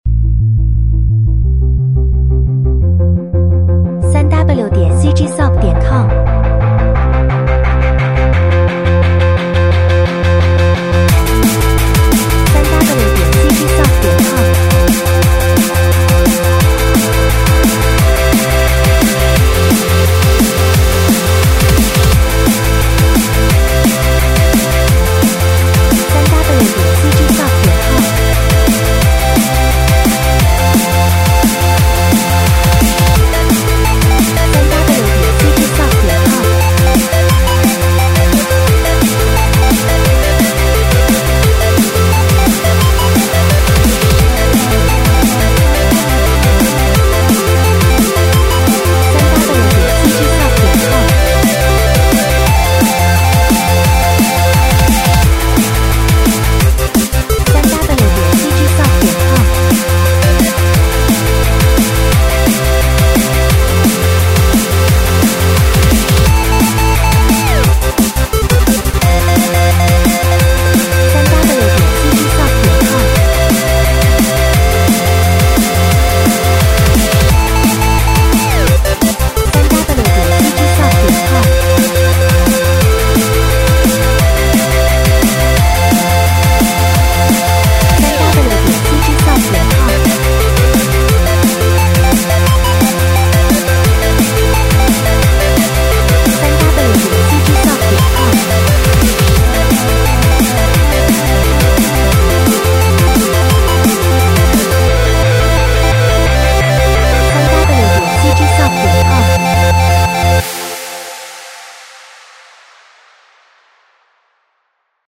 快速鼓和低音轨道与老式老式合成器80年代。